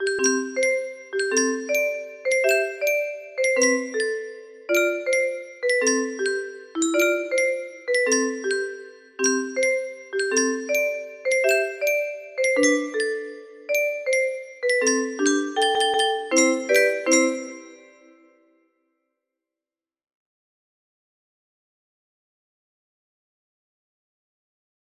30721 music box melody